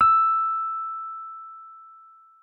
Rhodes_MK1
e5.mp3